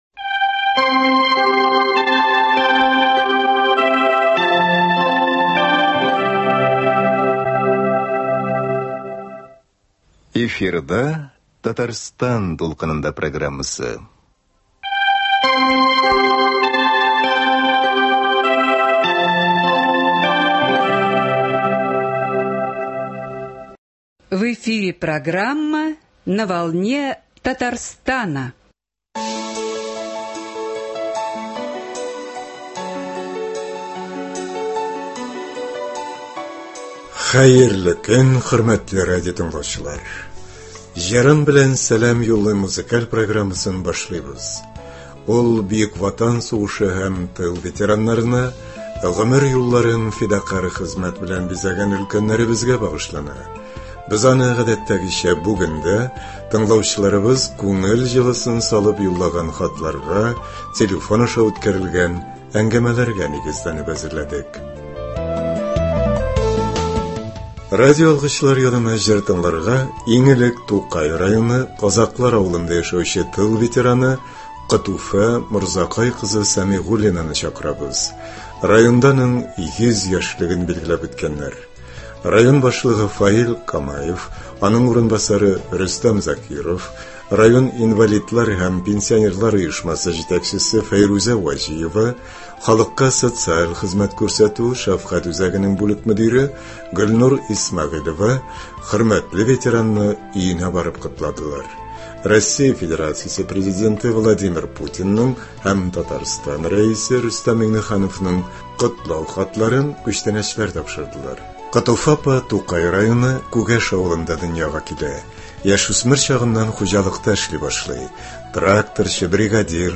музыкаль программасы